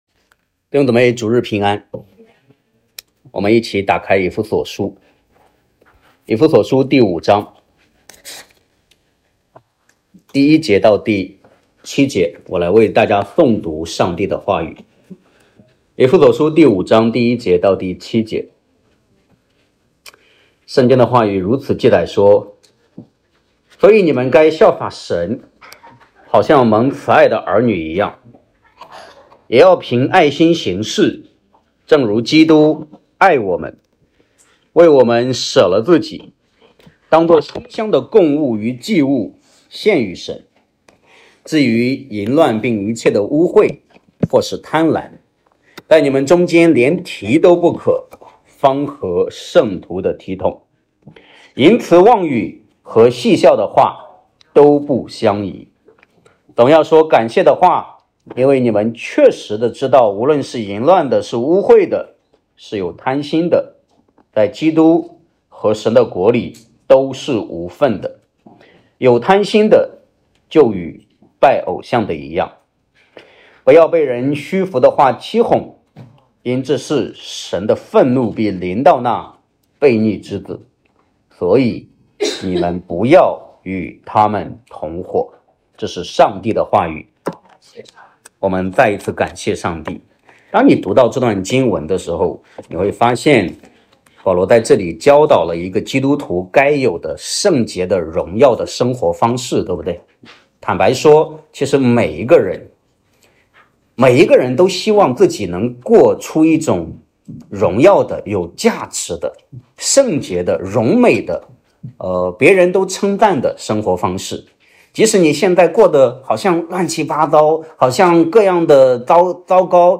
证道：蒙慈爱的儿女.mp3